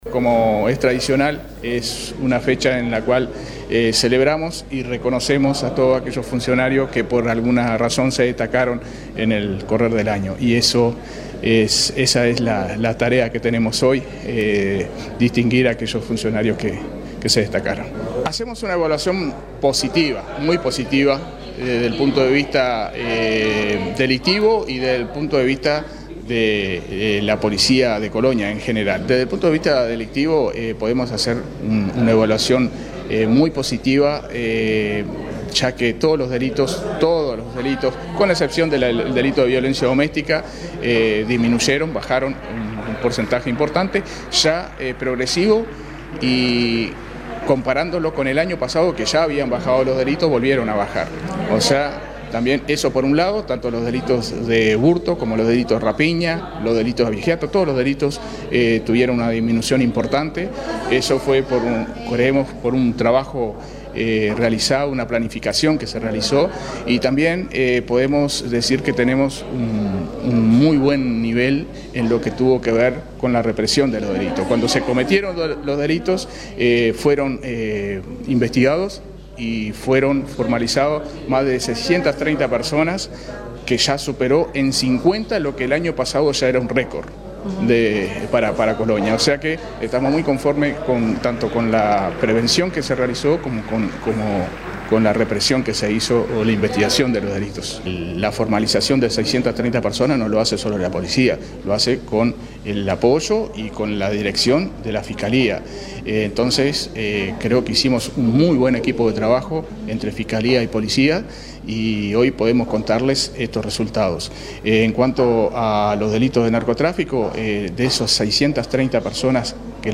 De estos, más de 200 estuvieron vinculados al narcotráfico, según lo informó el jefe de Policía del departamento, Fabio Quevedo.